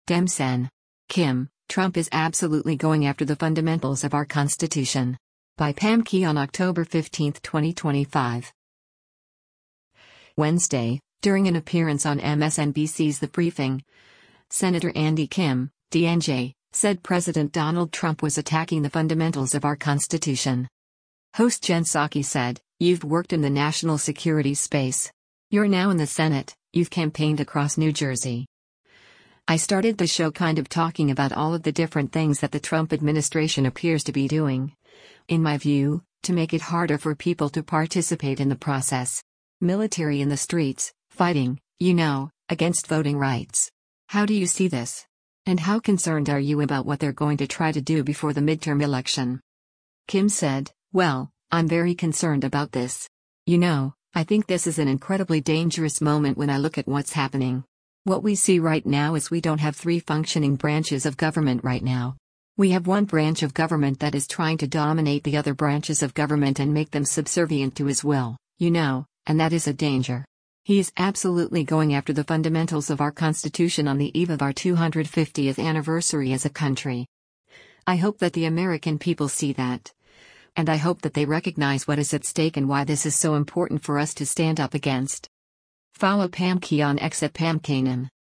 Wednesday, during an appearance on MSNBC’s “The Briefing,” Sen. Andy Kim (D-NJ) said President Donald Trump was attacking the “fundamentals of our Constitution.”